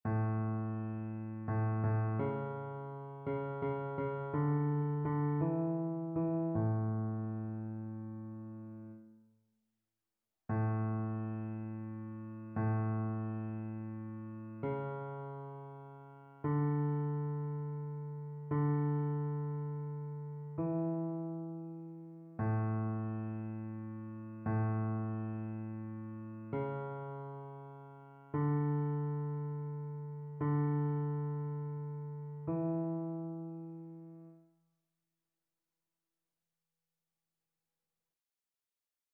Basse
annee-b-temps-ordinaire-32e-dimanche-psaume-145-basse.mp3